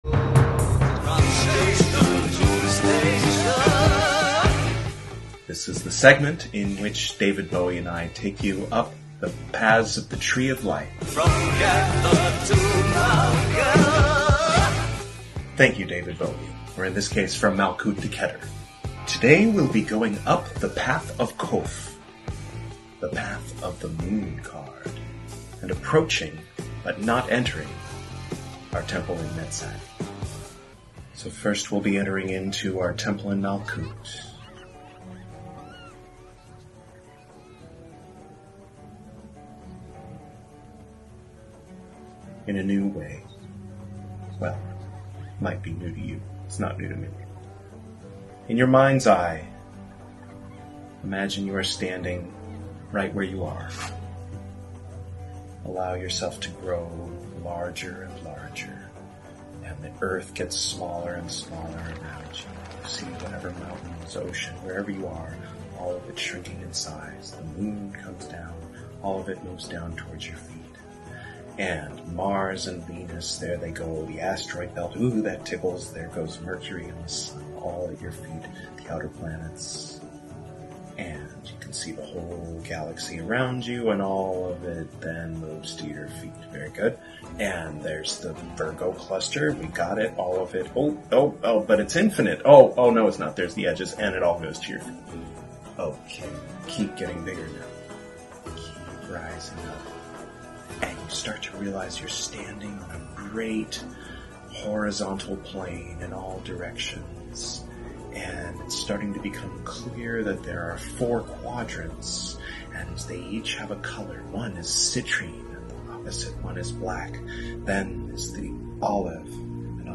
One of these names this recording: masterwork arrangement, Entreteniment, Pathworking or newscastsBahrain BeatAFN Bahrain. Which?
Pathworking